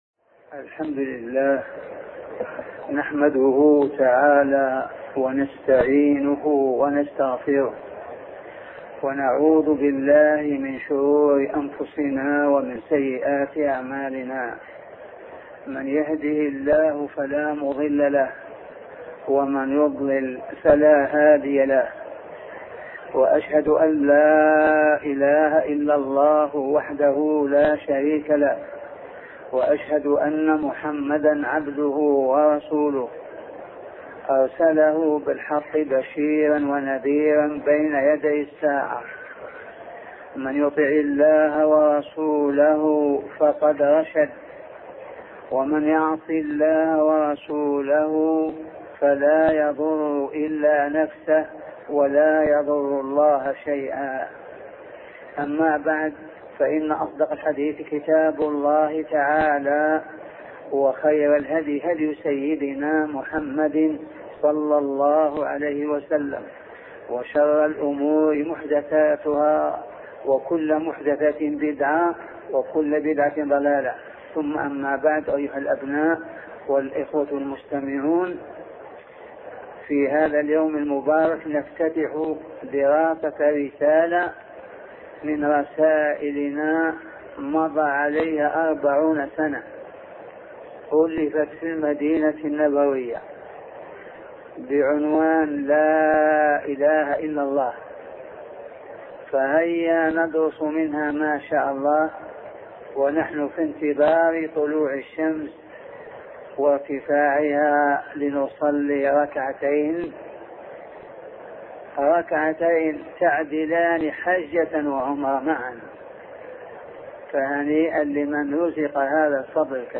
سلسلة محاطرات بعنوان معنى لا إله إلا الله